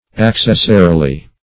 accessarily - definition of accessarily - synonyms, pronunciation, spelling from Free Dictionary Search Result for " accessarily" : The Collaborative International Dictionary of English v.0.48: Accessarily \Ac*ces"sa*ri*ly\, adv. In the manner of an accessary.
accessarily.mp3